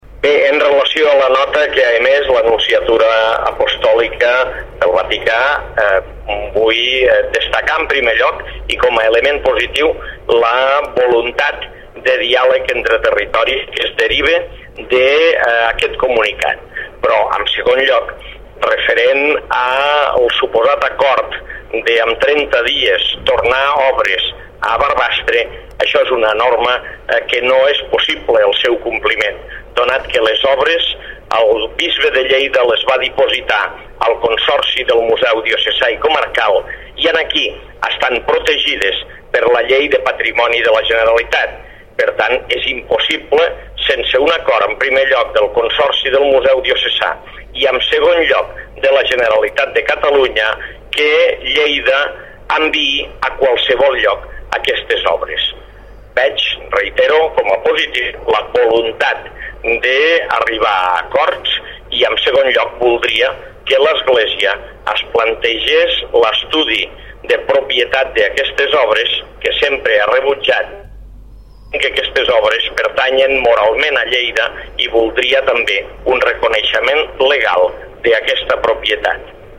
(adjuntem TALL de VEU d'aquestes declaracions) Compartir Facebook Twitter Whatsapp Descarregar ODT Imprimir Tornar a notícies Fitxers relacionats TALL de VEU Alcalde tema Diocesà (1.2 MB) T'ha estat útil aquesta pàgina?
tall-de-veu-alcalde-tema-diocesa